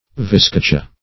Viscacha \Vis*ca"cha\, Viz-cacha \Viz-ca"cha\, n. [Sp.] (Zool.)